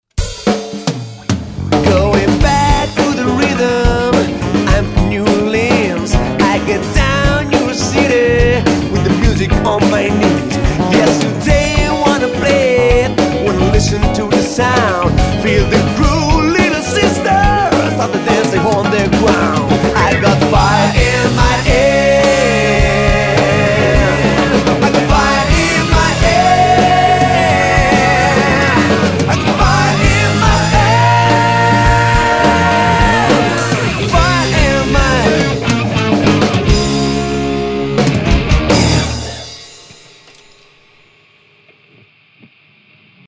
" Download the high-quality stereo audio of the song (about 1Mb, mp3 @ 160kbps)